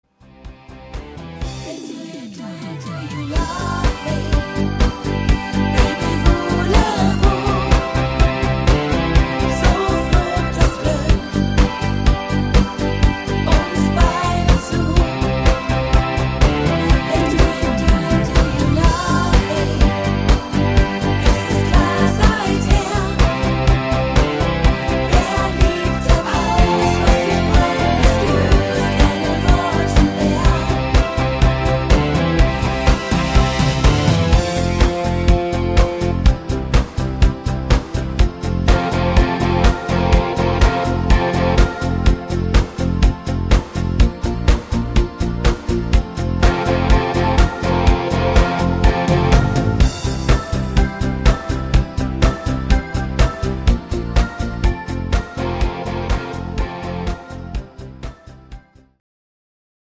gutes Schlager Duett